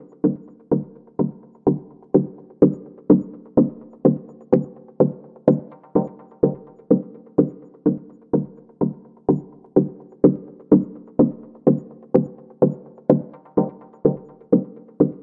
你好，8位方形合成器
标签： 100 bpm Techno Loops Synth Loops 1.62 MB wav Key : Unknown
声道立体声